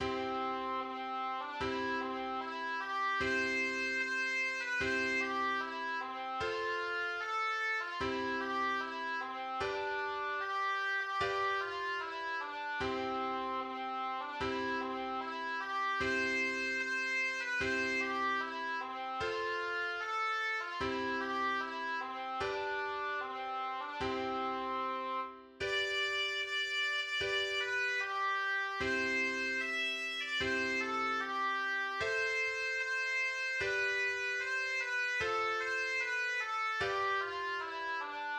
Marschlied einer Söldnertruppe